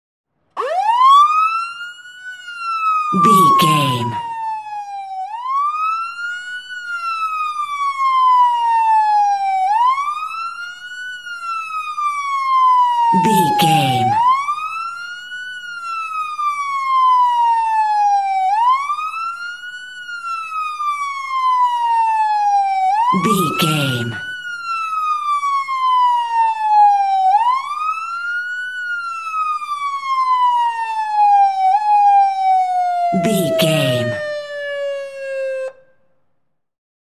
Ambulance Ext Large Siren
Sound Effects
urban
chaotic